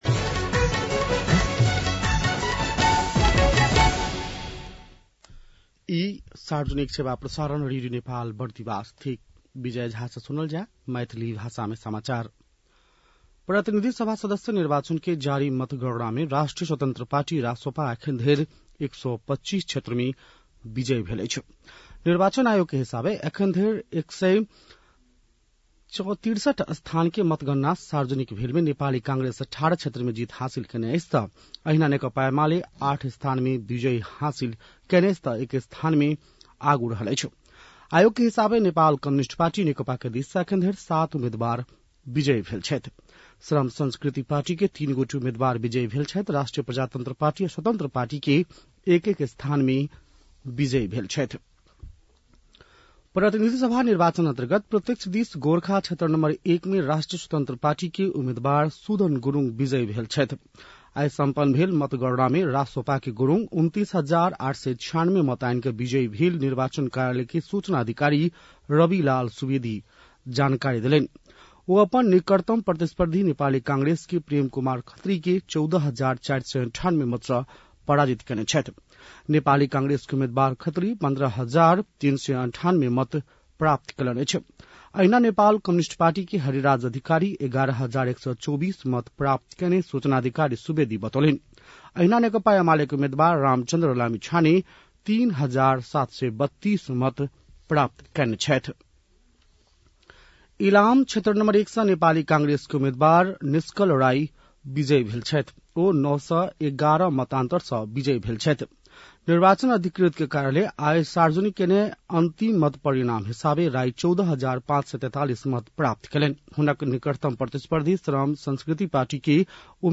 मैथिली भाषामा समाचार : २५ फागुन , २०८२
6.-pm-maithali-news-1-2.mp3